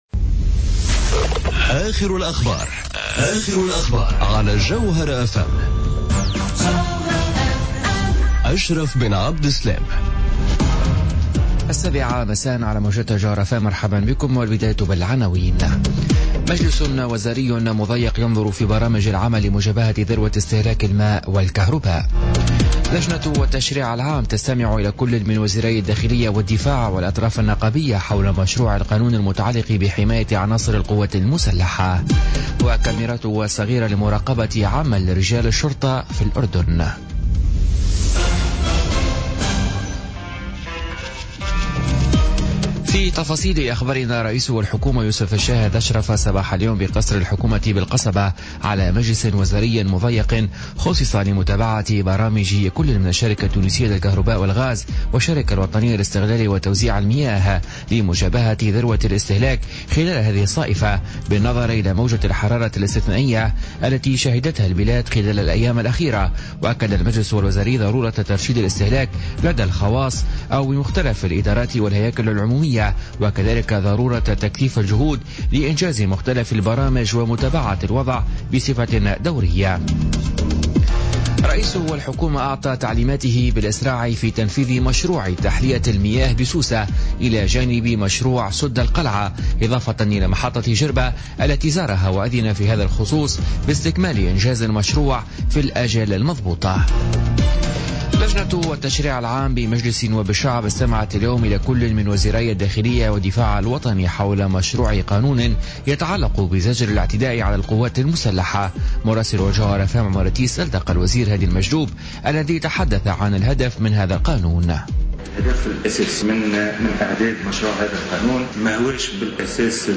نشرة أخبار السابعة مساء ليوم الخميس 13 جويلية 2017